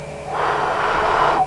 Blow Sound Effect
blow.mp3